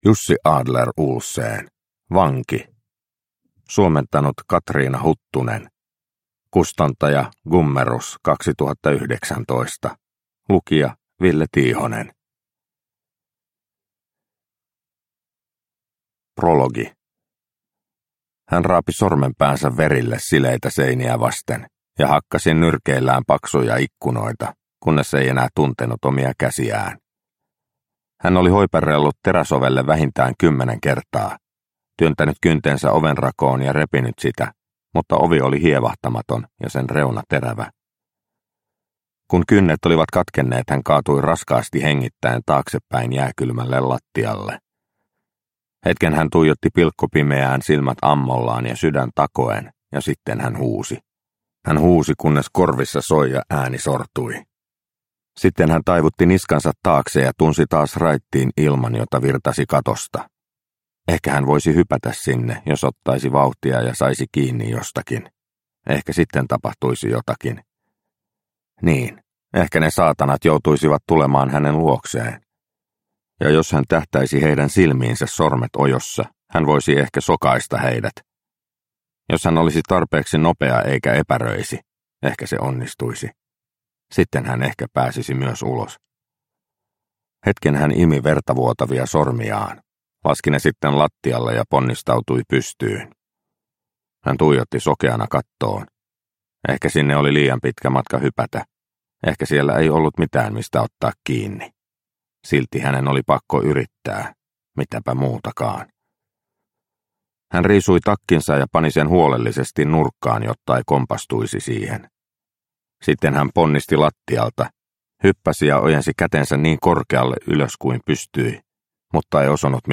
Vanki – Ljudbok – Laddas ner